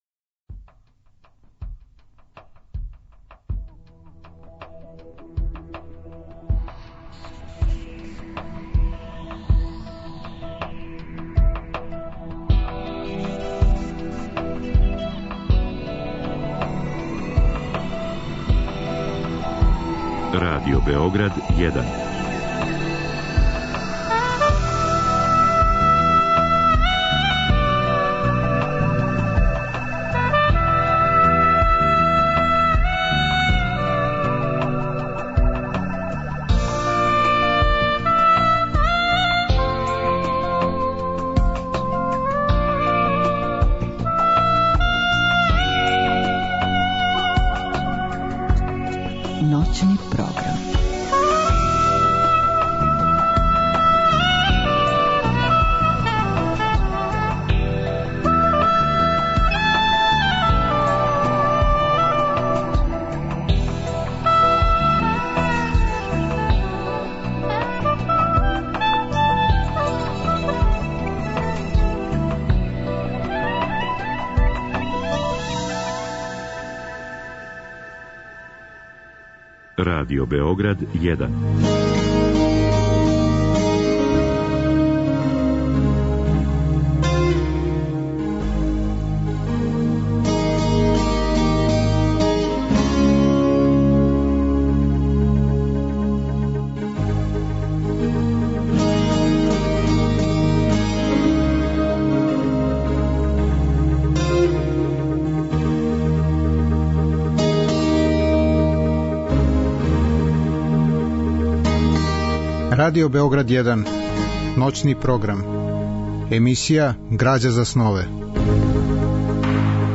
Разговор и добра музика требало би да кроз ову емисију и сами постану грађа за снове.
У другом делу емисије, од два до четири часa ујутро, слушаћемо делове радио-драма рађених по делима пољског писца Славомира Мрожека. Радио-драме су реализоване у продукцији Драмског програма Радио Београда.